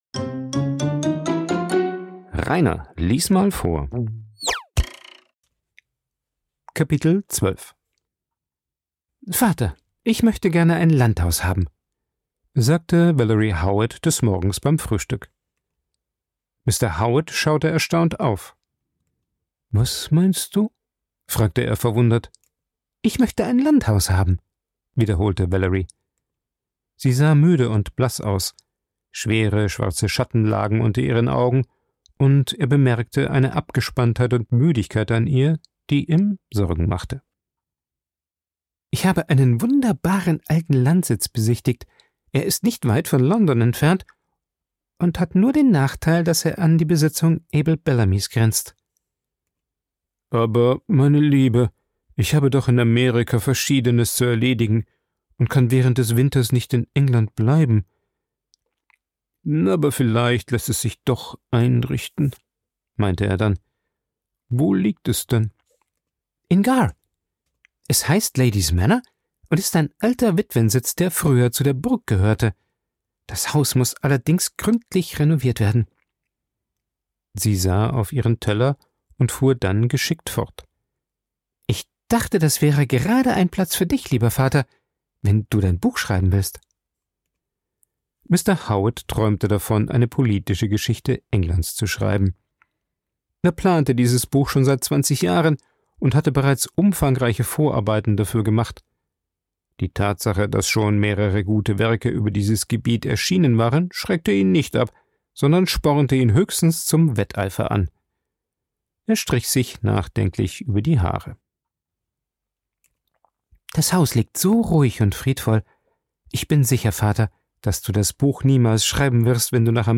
Ich lese Bücher vor, vorwiegend Klassiker der Weltliteratur, weil ich Spass am Vorlesen habe. Jeden 2. oder 3. Tag wird ein Kapitel eines Buches veröffentlicht, so dass mit der Zeit komplette Hörbücher entstehen.